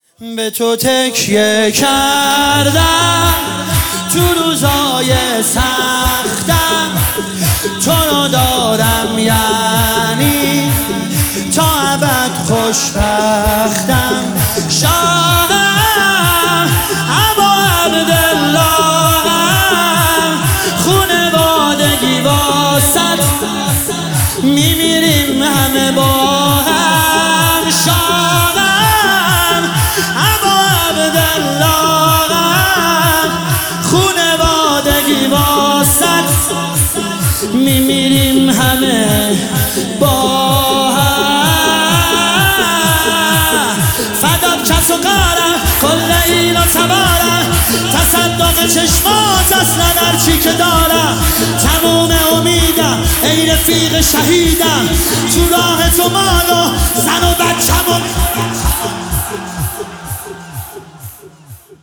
مداحی_شهادت حضرت زهرا